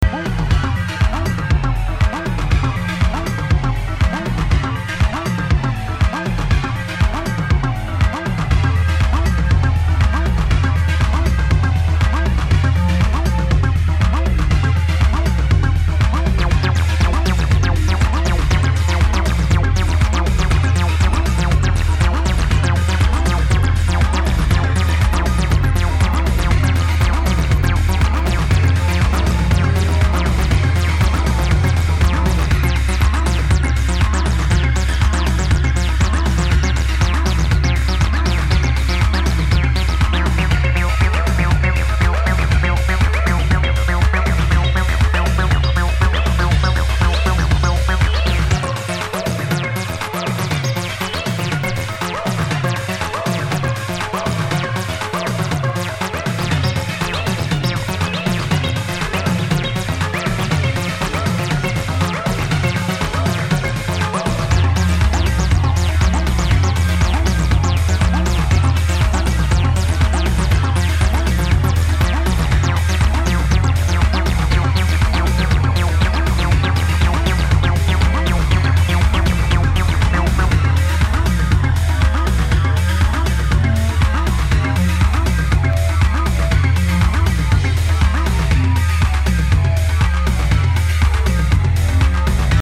techno music
raw acid and Detroit indebted electronic funk